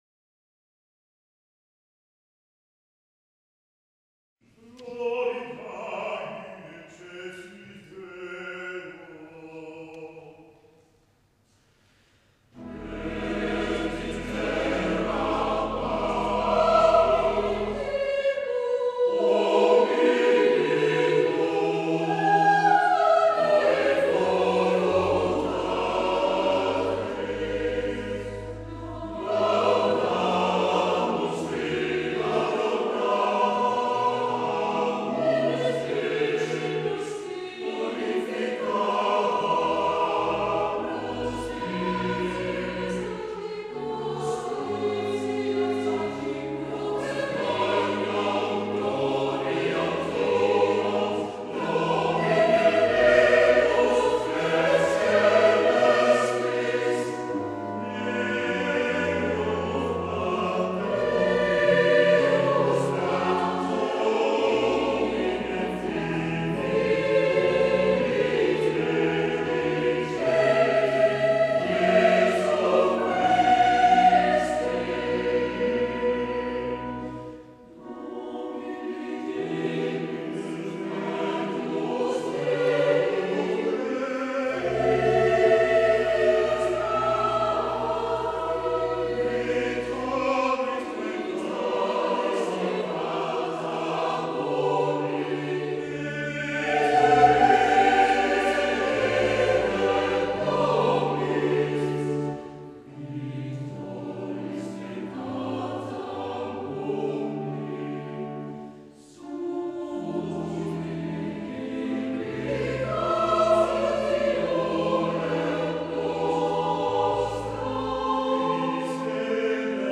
2010-2014 – Collegium Vocale Reformatum
Dit jaar is er een Cd opgenomen met Geestelijke Liederen en Oude Gezangen.